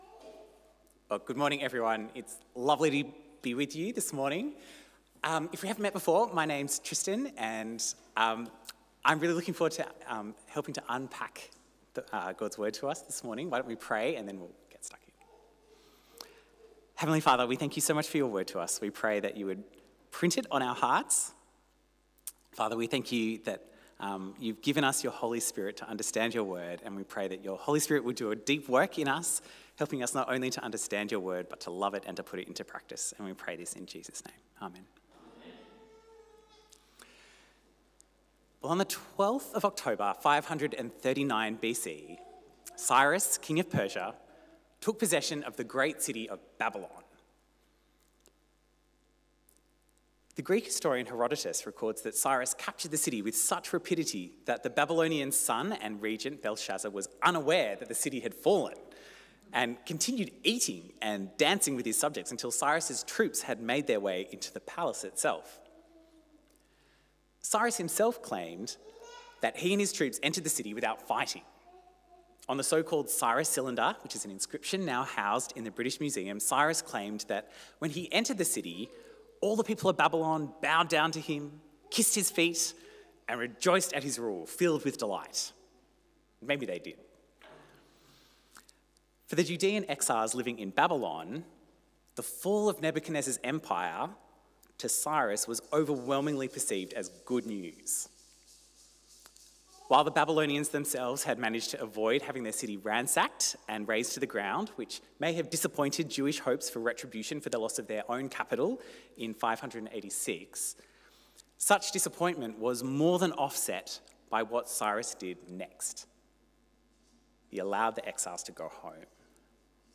A sermon on Haggai 1:1-15a